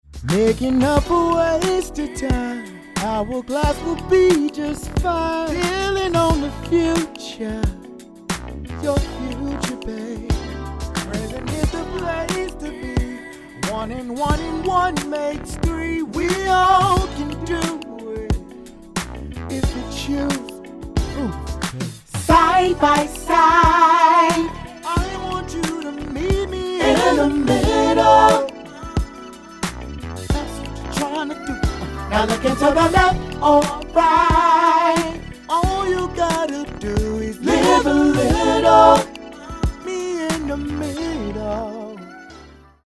In-The-Middle-RAW.mp3